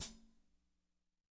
-16  HAT22-L.wav